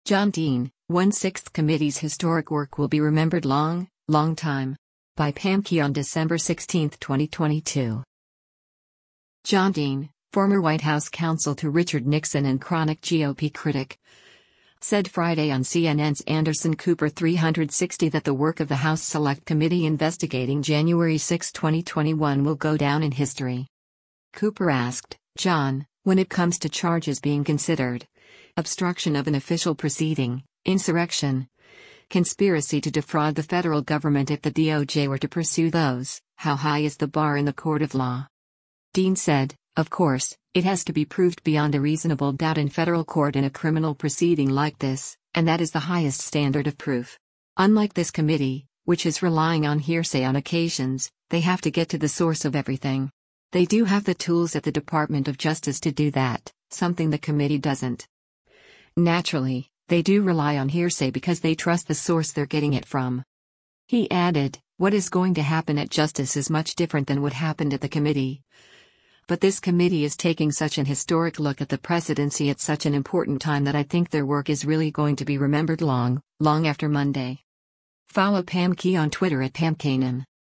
John Dean, former White House counsel to Richard Nixon and chronic GOP critic, said Friday on CNN’s “Anderson Cooper 360” that the work of the House Select Committee investigating January 6, 2021 will go down in history.